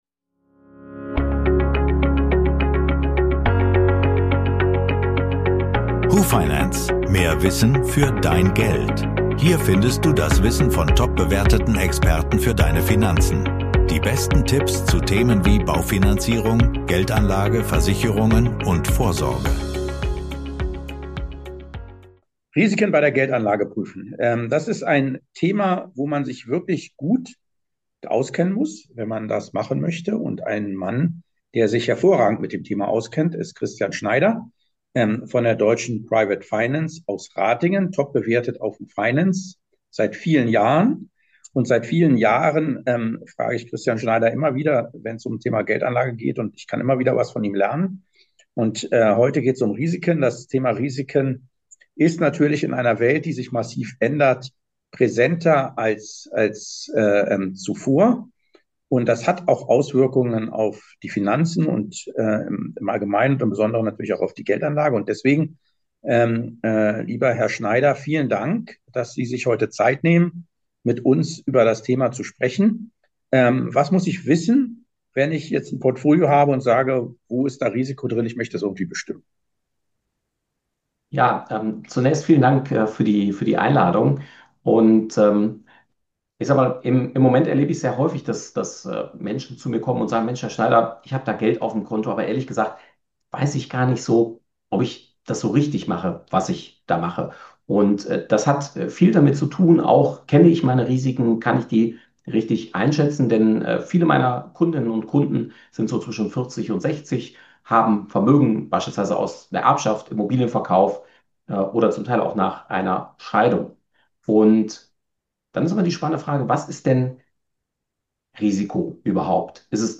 Experteninterview gehen wir hierauf ein.